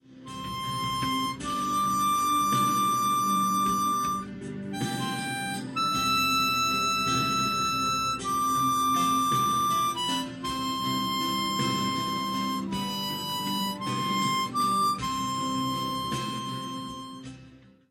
Here’s a beautiful Christmas song for you to play as a solo on the 10 hole harmonica. It was written in the South of France in 1847, combining the words of a poem with a new melody to celebrate the repair of the the local church organ.